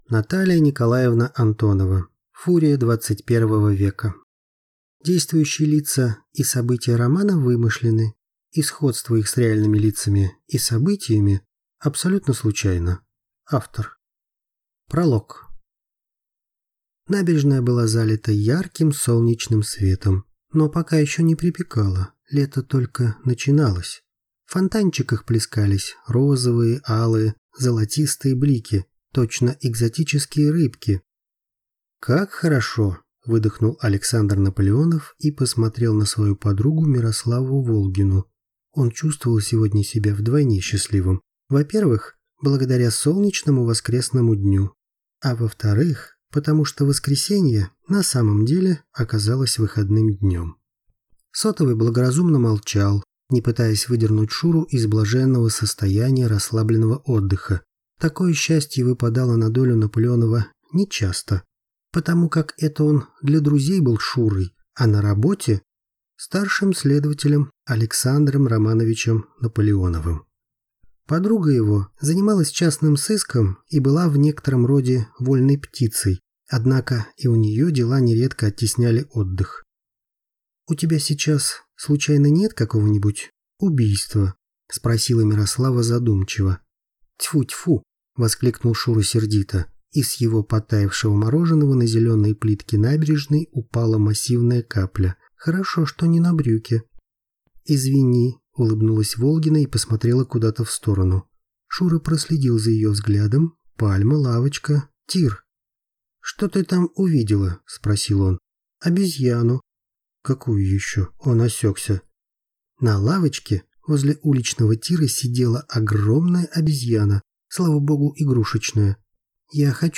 Аудиокнига Фурия XXI века | Библиотека аудиокниг